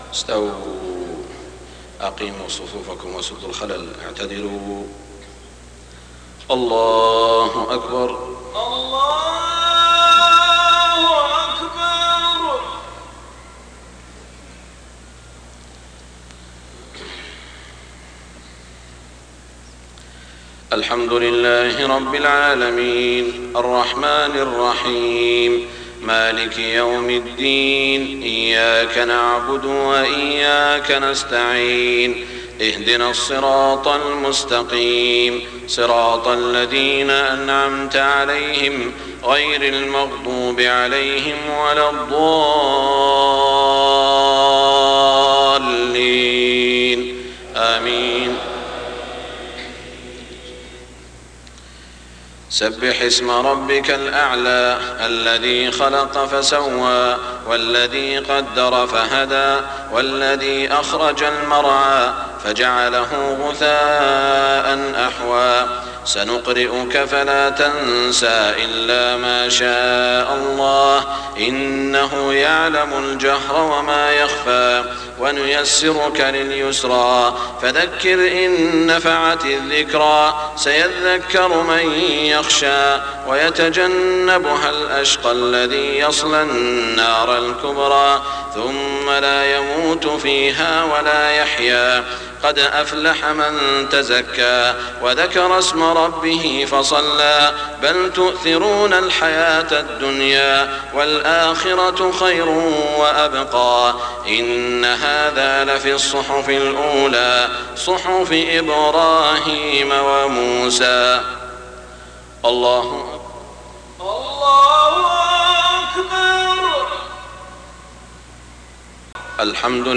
صلاة الجمعة 1-9-1422هـ سورتي الأعلى و الغاشية > 1422 🕋 > الفروض - تلاوات الحرمين